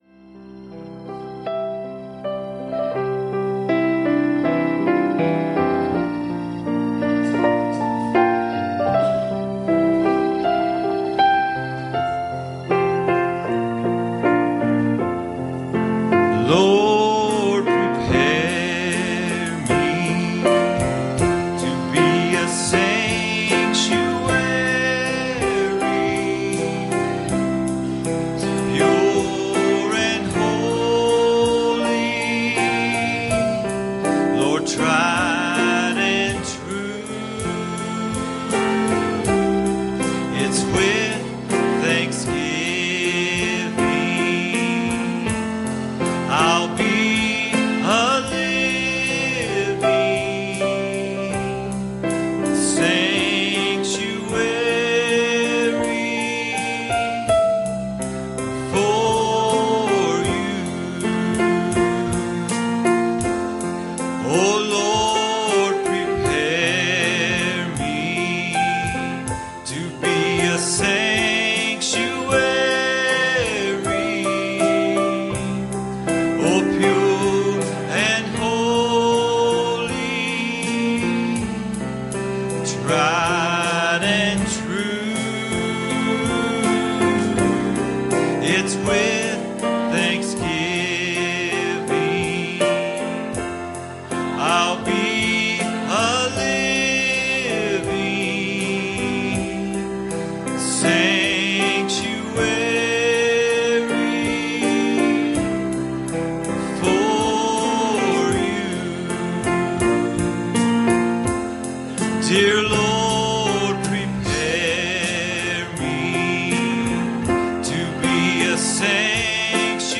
Passage: John 11:39 Service Type: Wednesday Evening